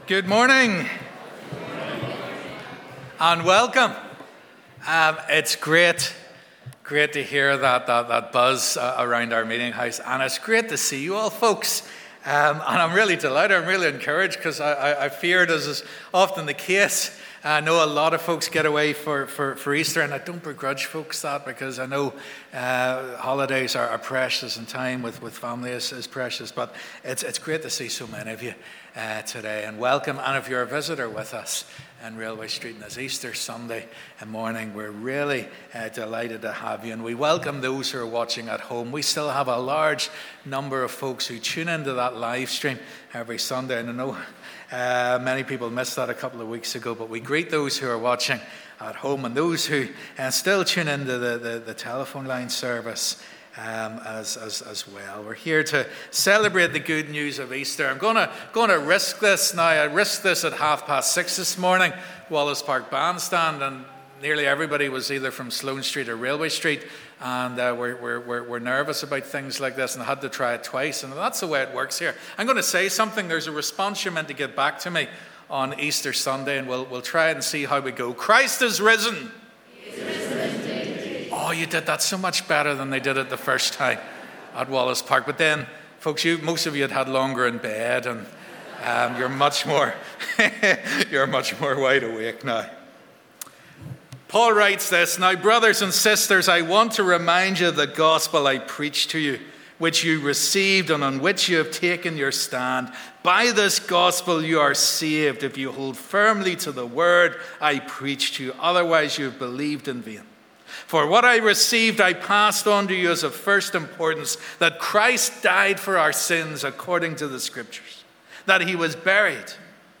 'Easter Family Service'